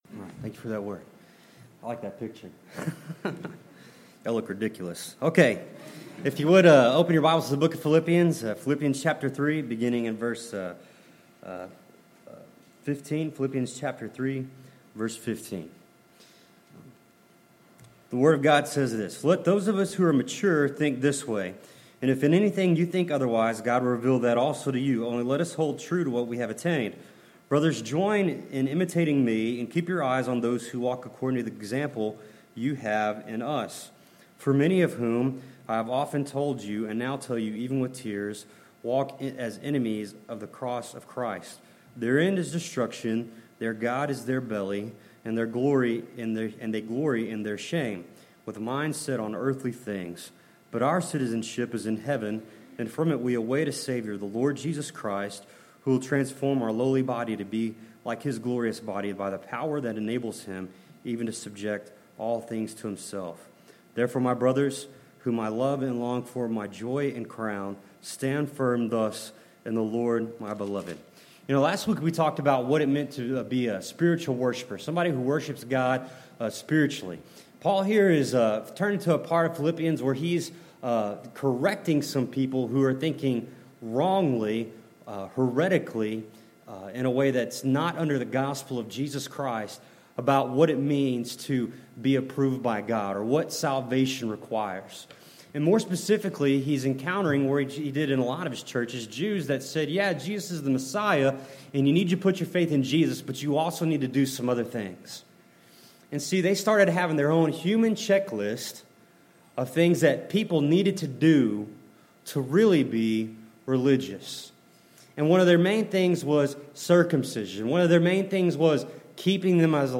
Passage: Philippians 3:15-4:1 Service Type: Sunday Morning